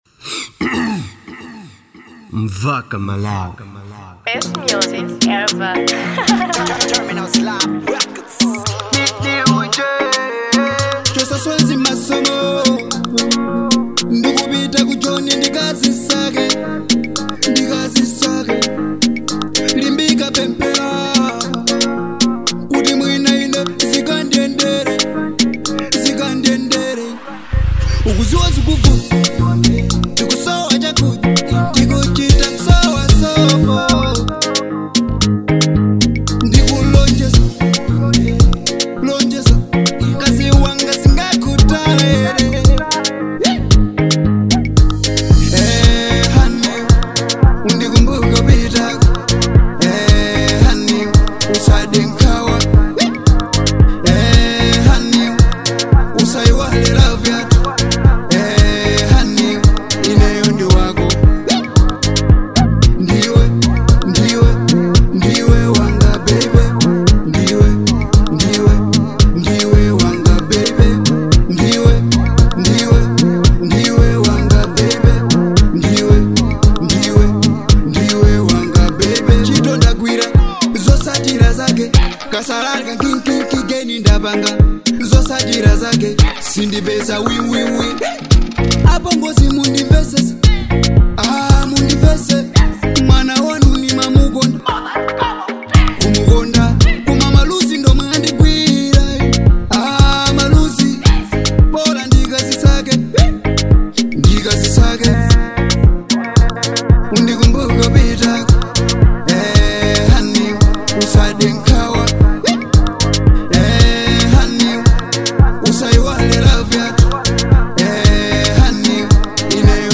type:smooth